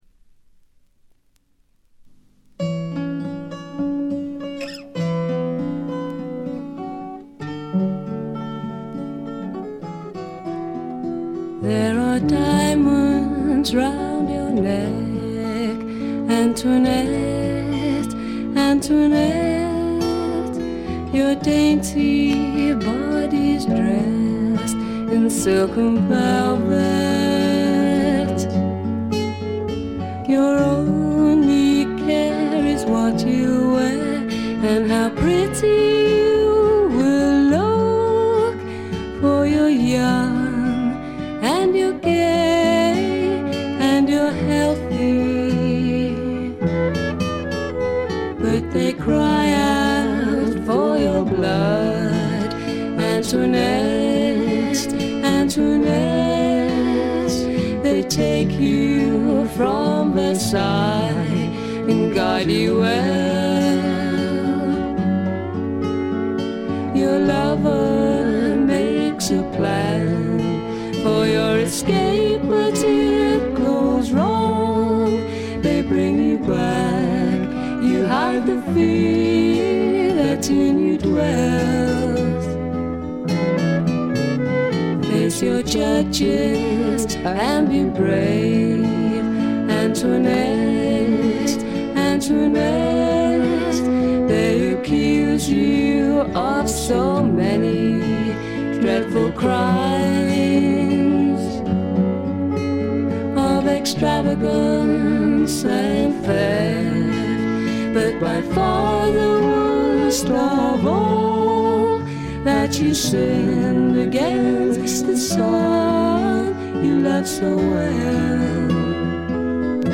ところどころでチリプチやバックグラウンドノイズ。
試聴曲は現品からの取り込み音源です。